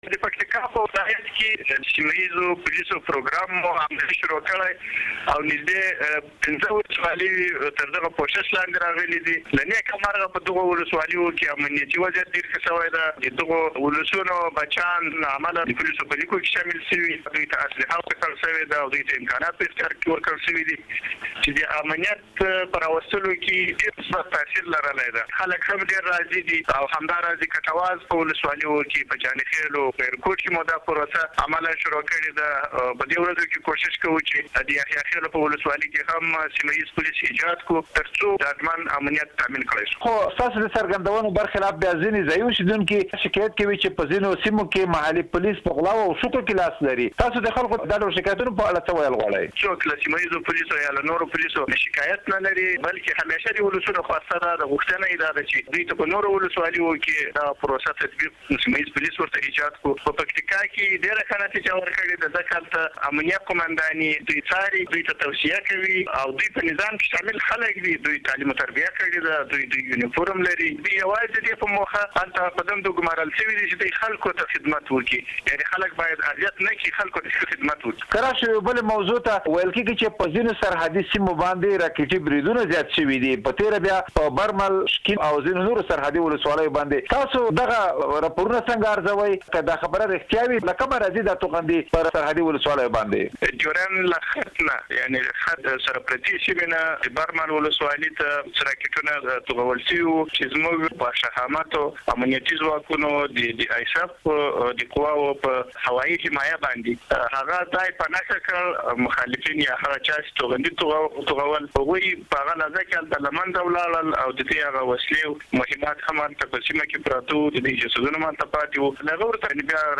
د پکتيکا له والي محب الله صميم سره مرکه